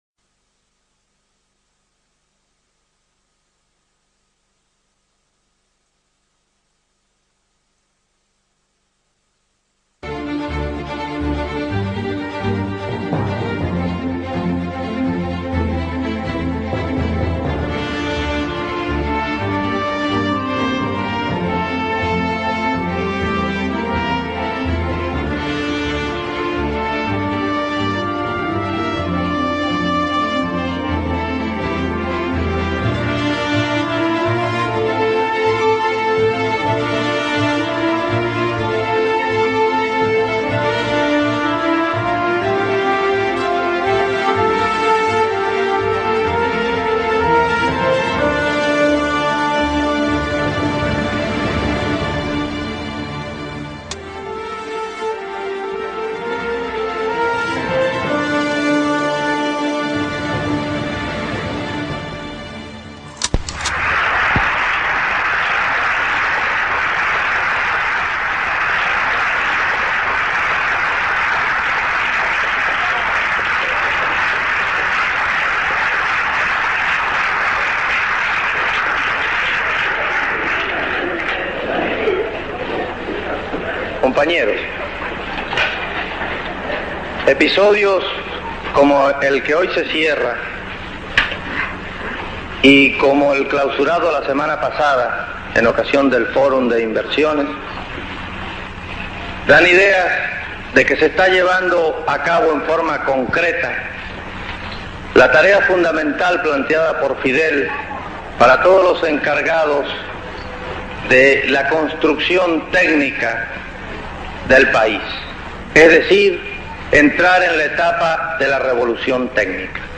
■ Discurso en el Fórum de Energía Eléctrica - La Habana, noviembre de 1963.
che-foro-energia-electrica-parte1.mp3